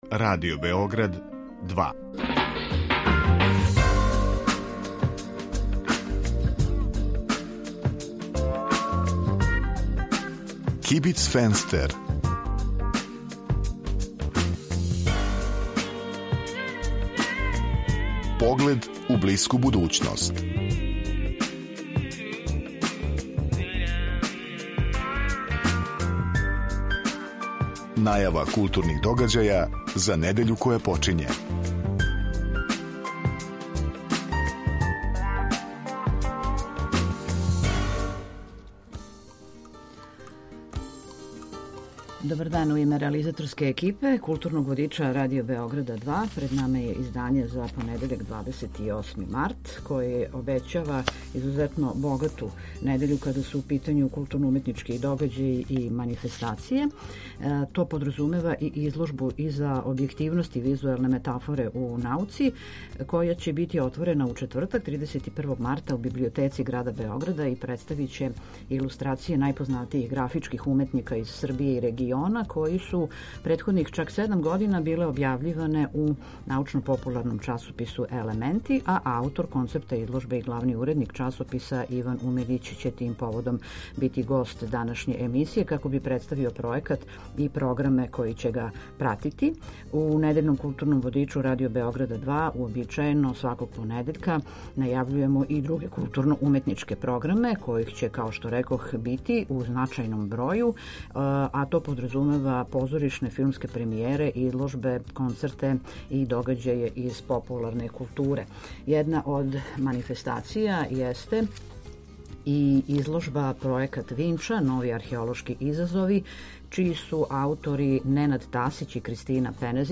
У некој врсти културног информатора за недељу која је почела, чућете аргументован избор и препоруку новинара и уредника РБ 2 из догађаја у култури који су у понуди у тој недељи. Свака емисија има и госта (госте), чији избор диктира актуелност – то је неко ко нешто ради у тој недељи или је везан за неки пројекат који је у току.